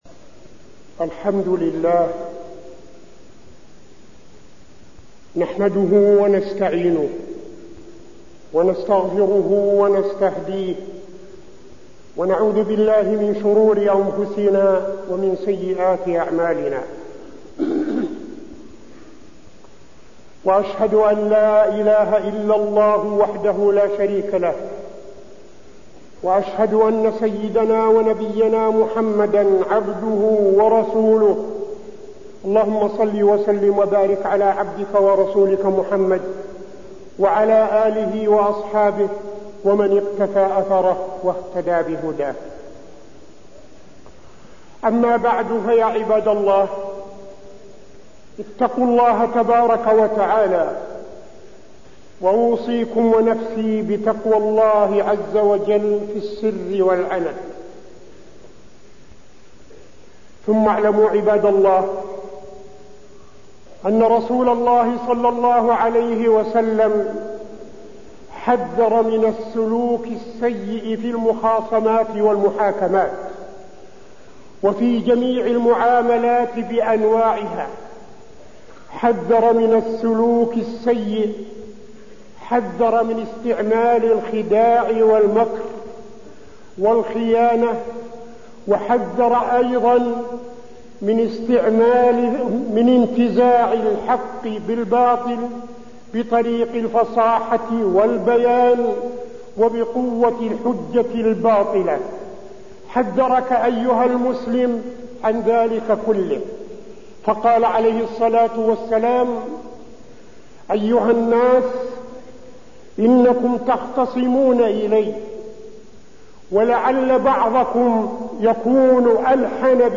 خطبة التحاكم والحجة وفيها: تحذير النبي من السلوك السيء في المحاكمات، وأهمية التزام الصدق في المحاكمات، والتحذير من أكل أموال الناس بالباطل
تاريخ النشر ٢٤ جمادى الآخرة ١٤٠٥ المكان: المسجد النبوي الشيخ: فضيلة الشيخ عبدالعزيز بن صالح فضيلة الشيخ عبدالعزيز بن صالح التحاكم والحجة